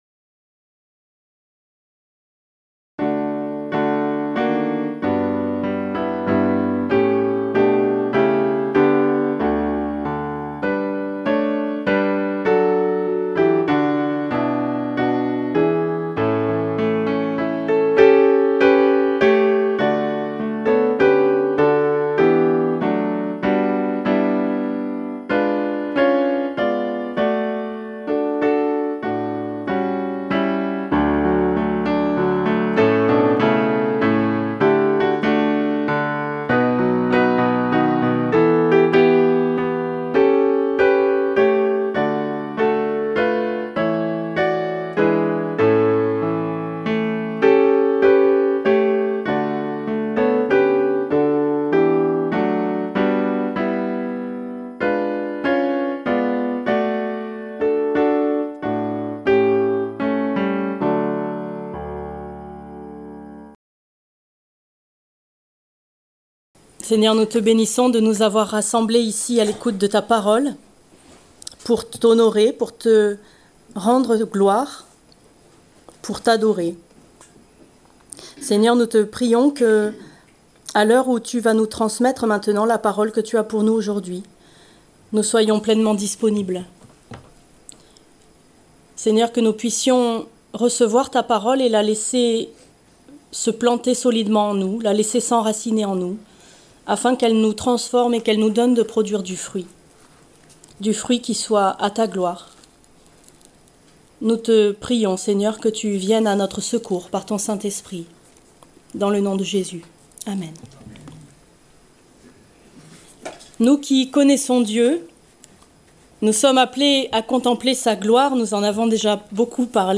Prédication du 02 mars 2025.